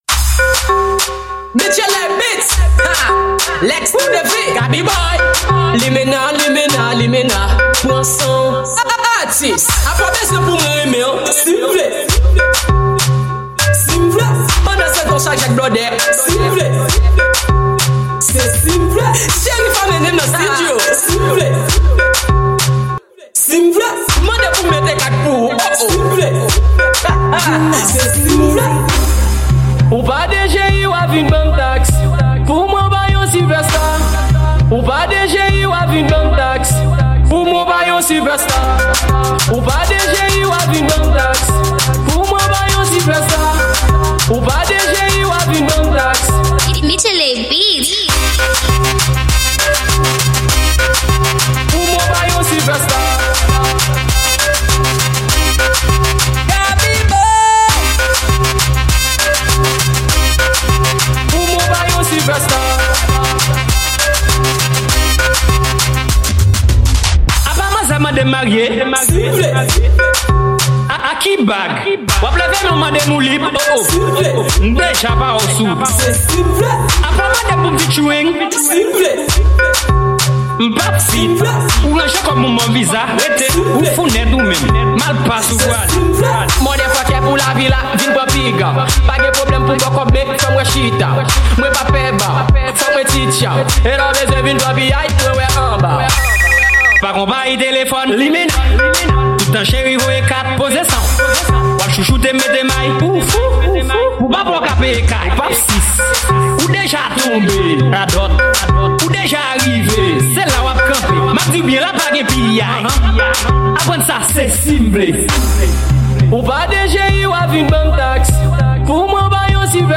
Genre: Afro.